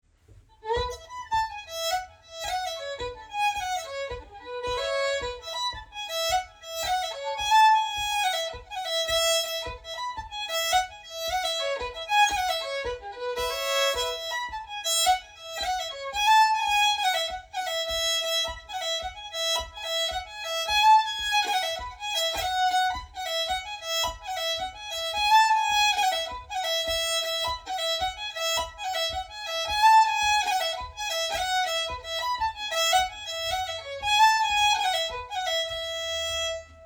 Sessions are open to all instruments and levels, but generally focus on the melody.
Type Jig Key E